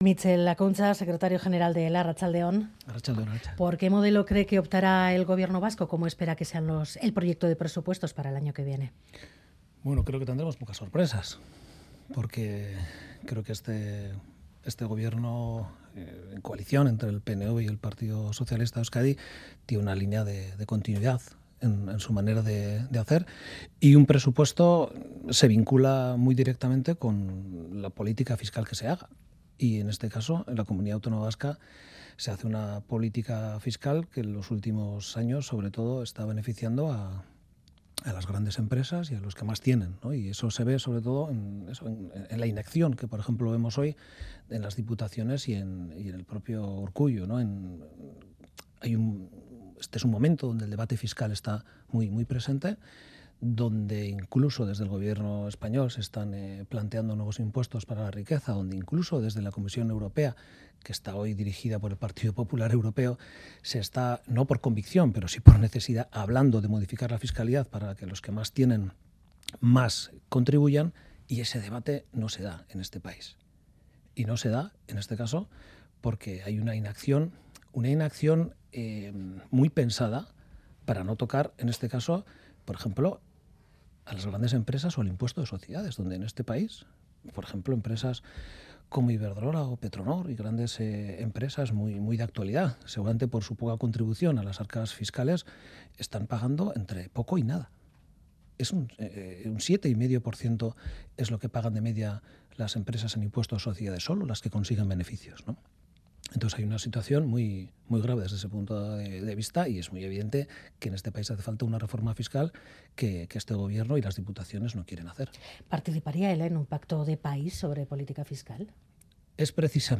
Entrevistado en Ganbara